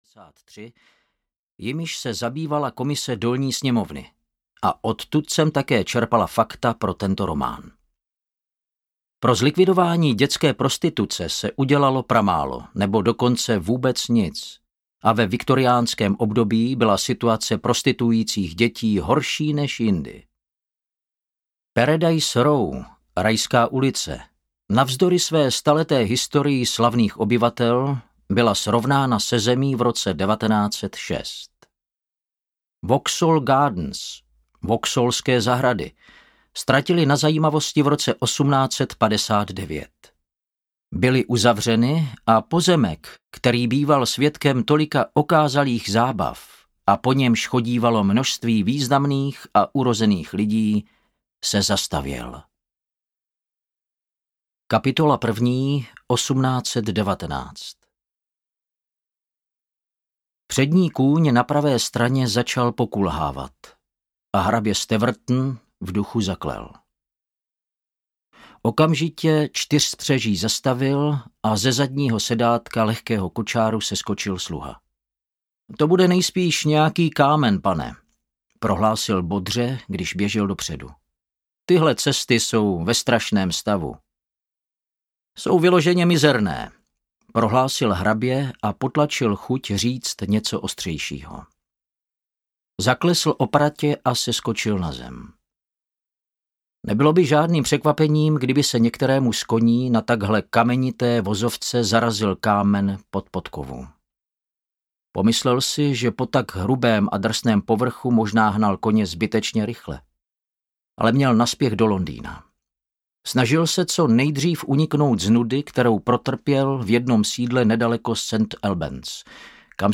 Lhostejný poručník audiokniha
Ukázka z knihy
lhostejny-porucnik-audiokniha